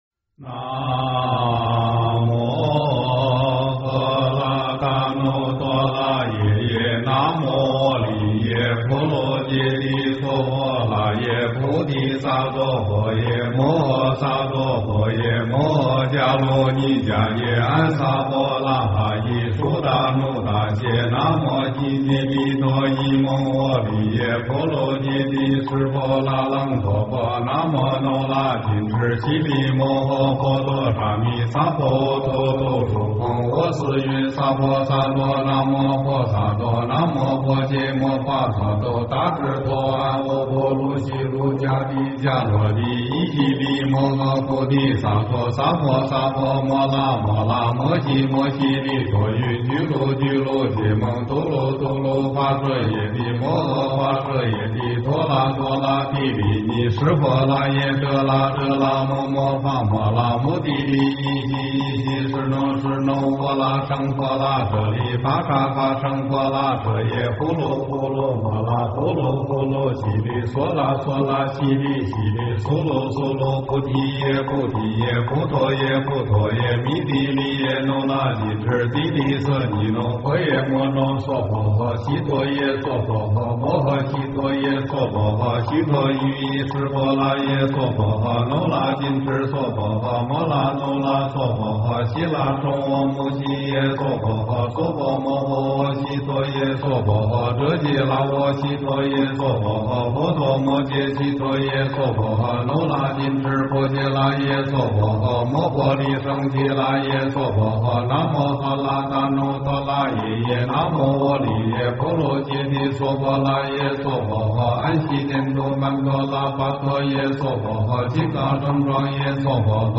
经忏
佛音 经忏 佛教音乐 返回列表 上一篇： 六字大明咒(唱诵版